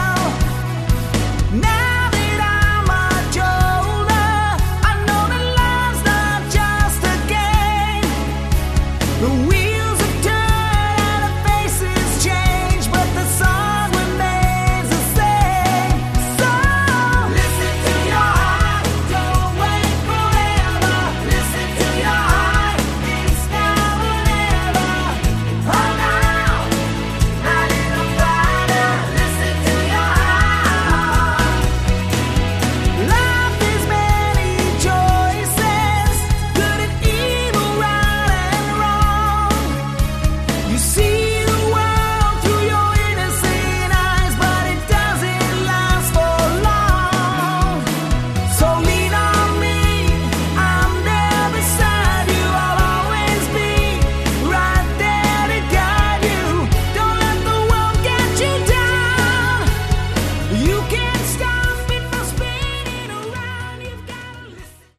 Category: AOR
guitar, bass, keyboards
lead guitars